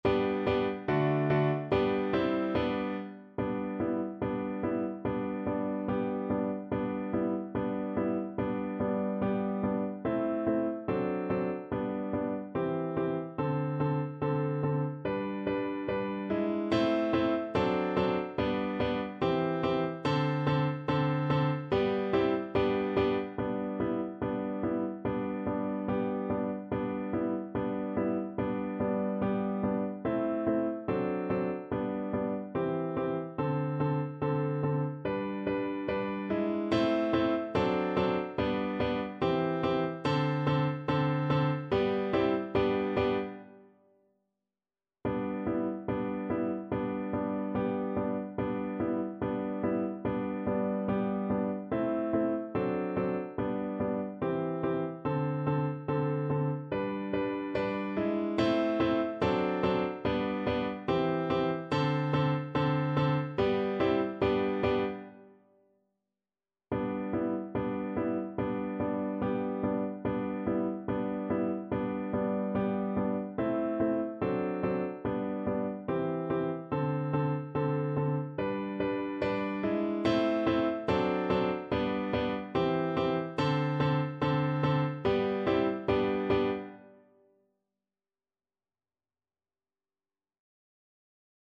kolęda: Pójdźmy wszyscy do stajenki (na flet i fortepian)
Symulacja akompaniamentu